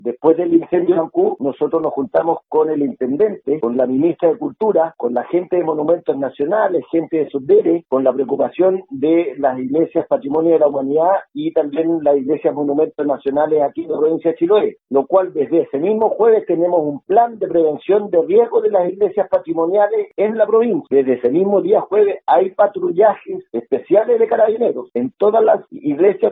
El gobernador de Chiloé, Fernando Bórquez, dijo que han instaurado un protocolo de resguardo especial en todos los templos patrimoniales del archipiélago.